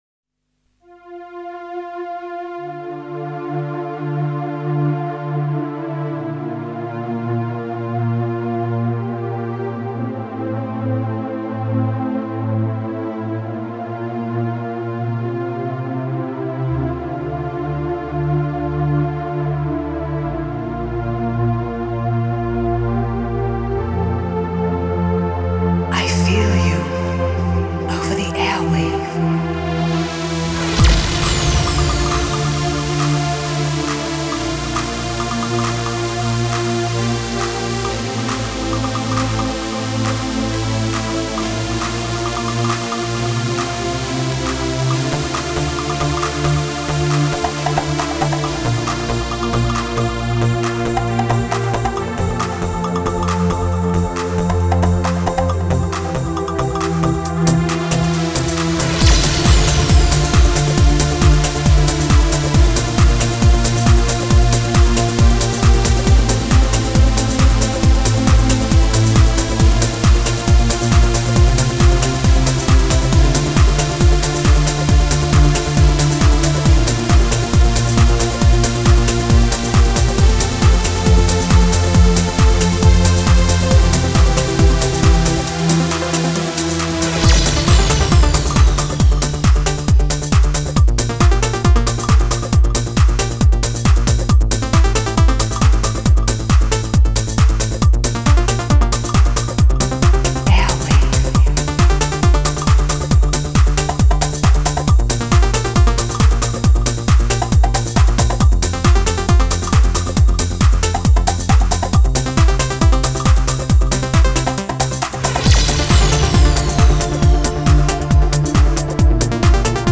progressive trance